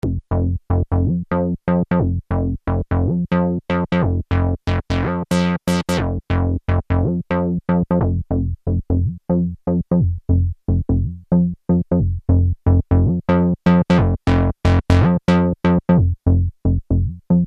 These are a few simple demo sounds I just came up with:
Housie bassline.
Tempo 120BPM
A nice sound but it has little in the way of bottom end.
moogbass.mp3